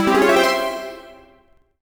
tutorial_enter_circle_01.wav